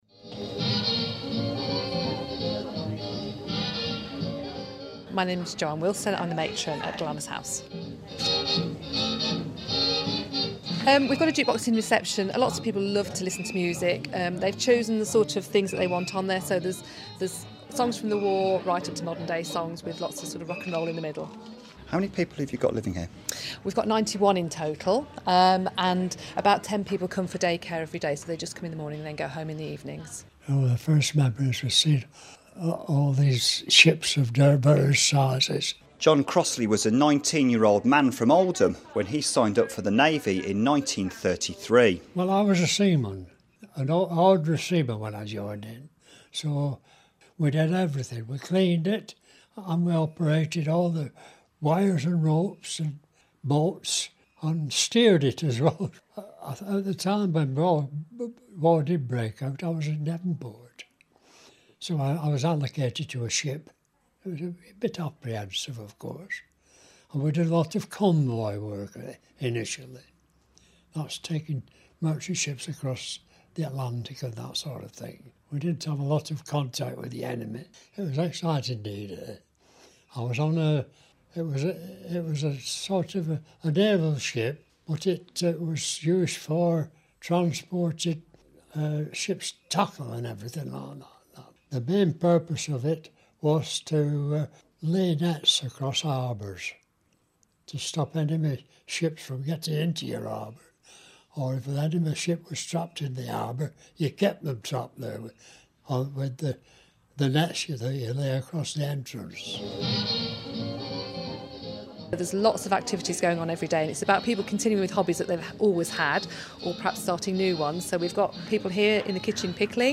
On Armistice Day we hear a D-Day Veteran's memories